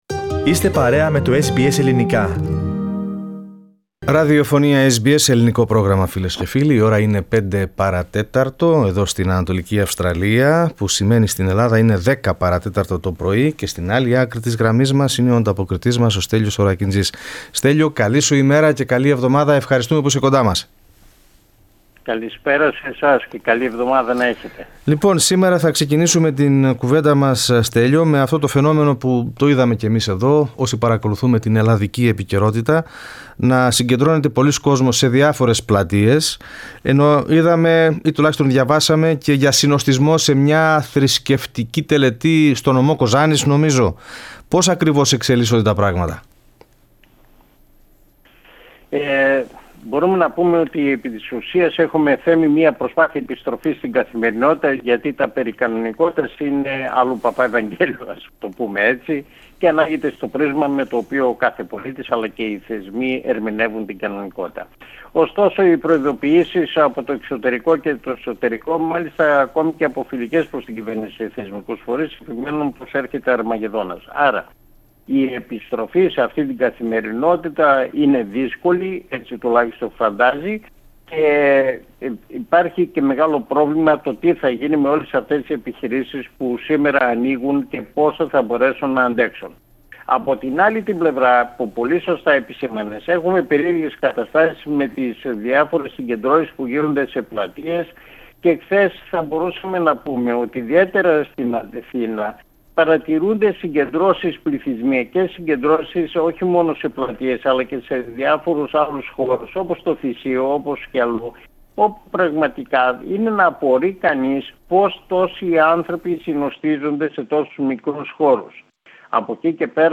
Περισσότερα στην ανταπόκριση